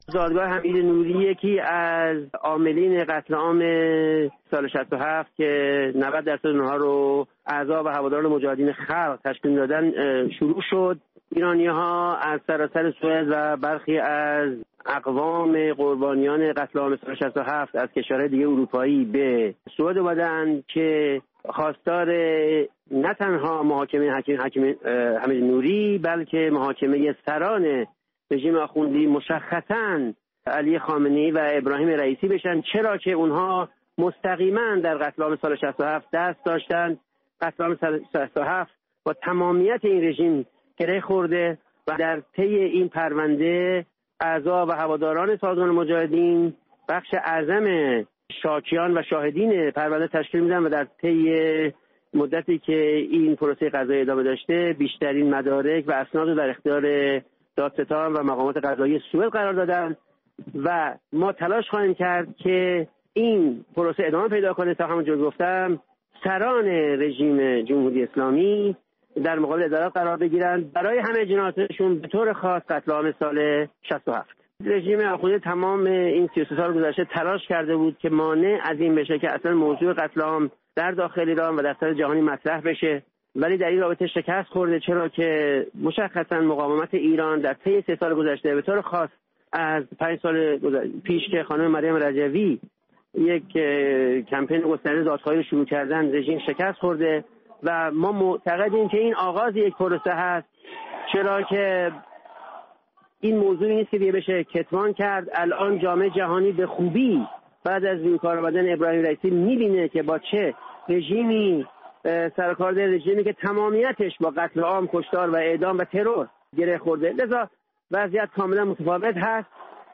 همراه با شاکیان پرونده و خانواده قربانیان کشتارهای دهه ۶۰، در استکهلم، پایتخت سوئد
دادگاه حمید نوری، دادیار دادگاه انقلاب اسلامی و از عاملان کشتار دهه ۶۰، که در سوئد دستگیر شده است، روز سه‌شنبه در استکهلم، پایتخت سوئد، آغاز شده است. همزمان، بسیاری از شاکیان پرونده و خانواده قربانیان کشتارهای دهه ۶۰ با در دست داشتن پرچم‌ها یا تصاویر قربانیان، در مقابل دادگاه تجمع کردند.